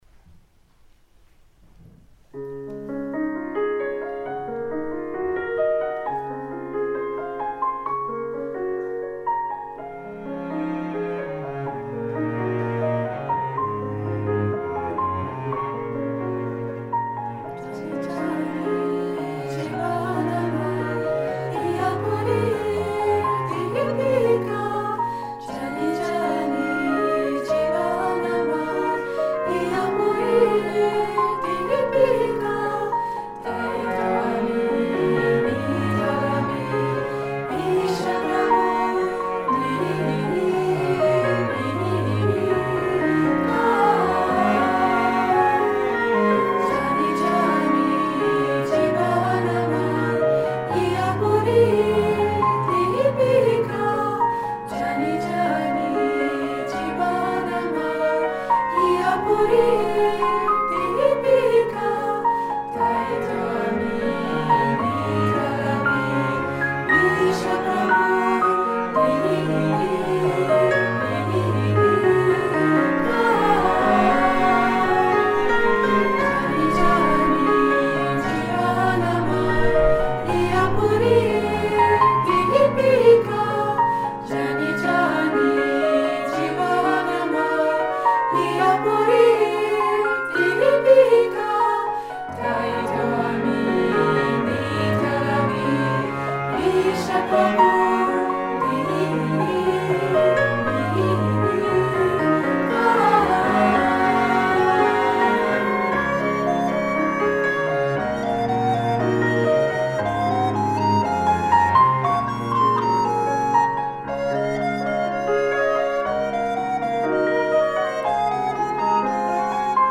all female vocal and instrumental ensemble